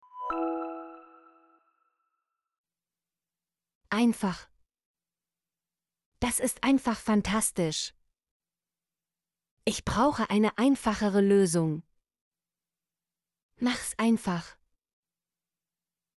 einfach - Example Sentences & Pronunciation, German Frequency List